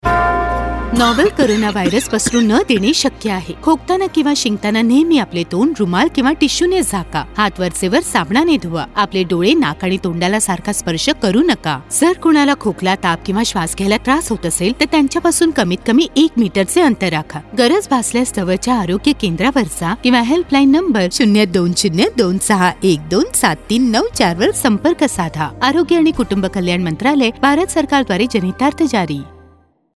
Radio PSA
5154_Cough Radio_Marathi.mp3